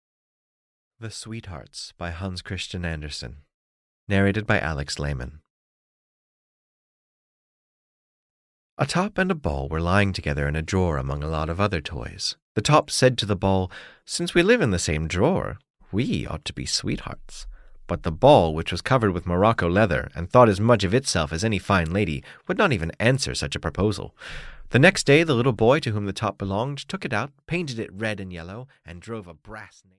The Sweethearts (EN) audiokniha
Ukázka z knihy